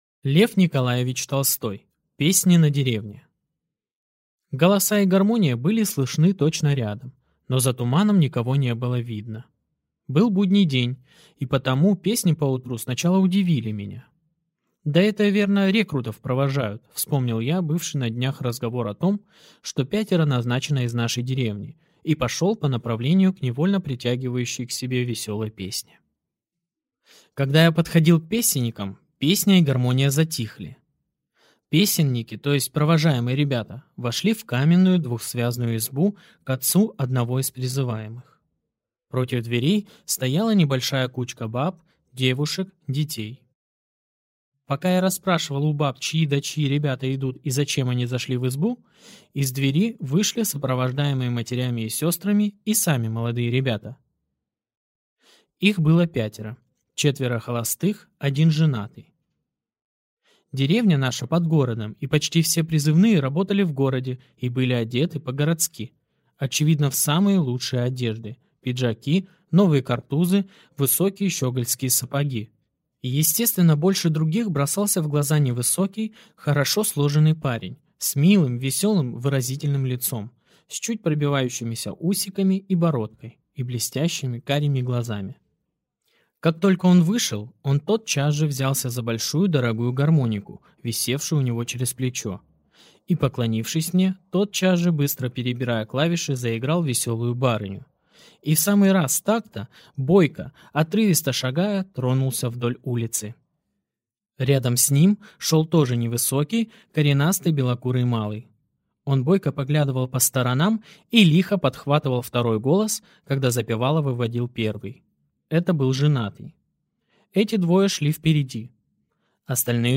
Аудиокнига Песни на деревне | Библиотека аудиокниг